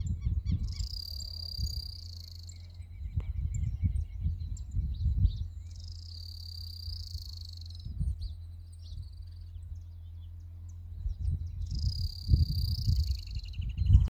Short-billed Canastero (Asthenes baeri)
Condition: Wild
Certainty: Observed, Recorded vocal